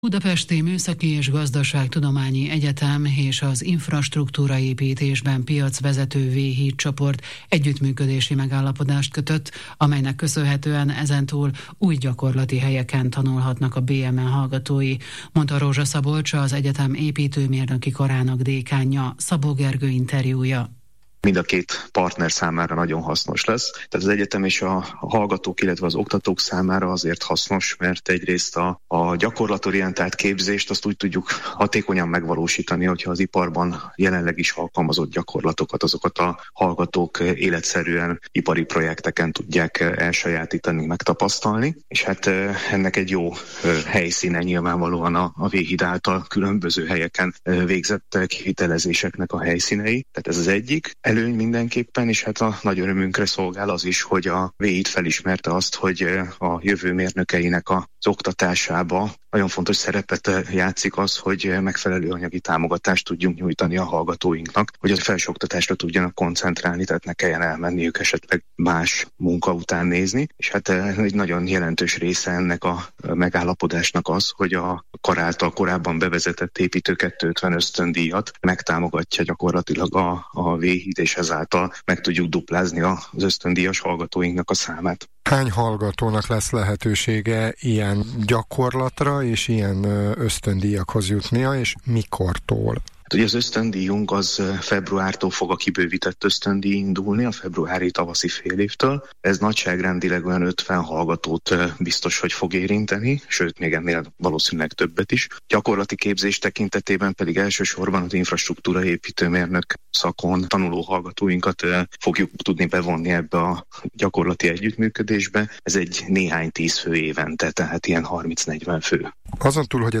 Az interjú